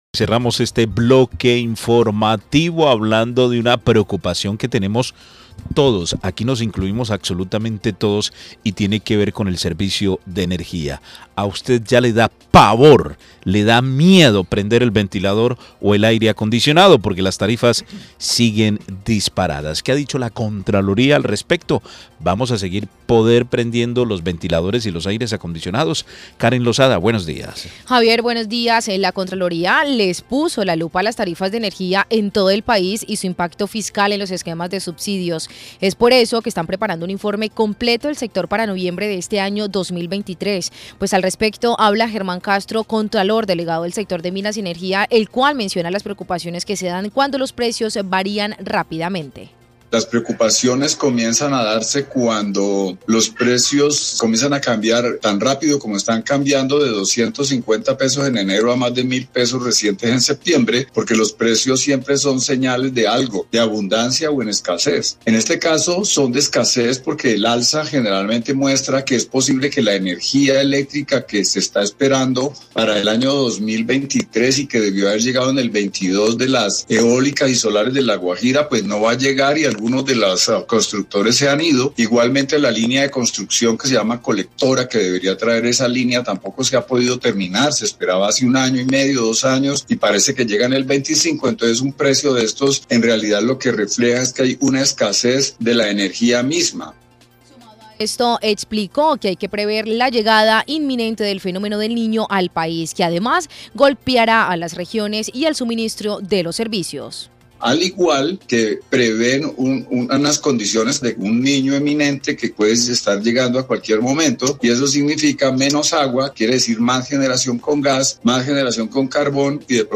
La Contraloría les puso la lupa a las tarifas de energía en el país y su impacto fiscal en los esquemas de subsidios, por eso, están preparando un informe completo del sector para noviembre de este año. Al respecto habla German Castro, contralor delegado del sector Minas y Energía, el cuál menciona las preocupaciones que se dan cuando los precios varían rápidamente.